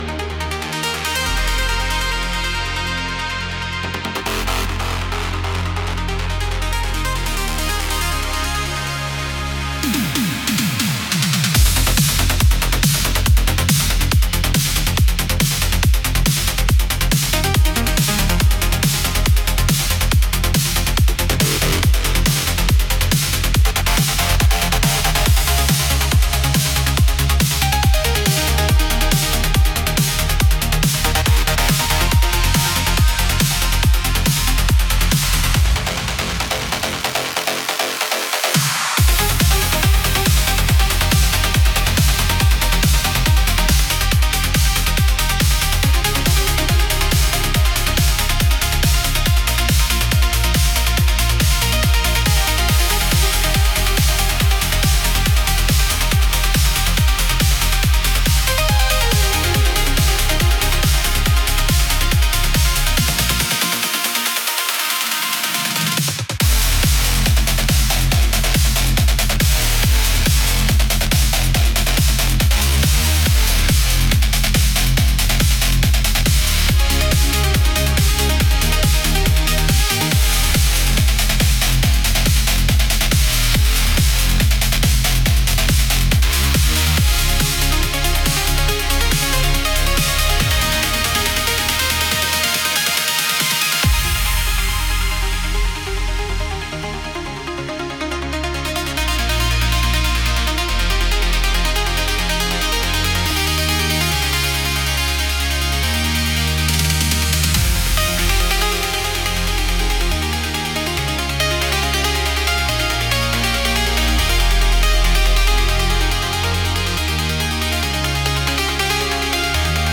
Heavier. Darker. More aggressive.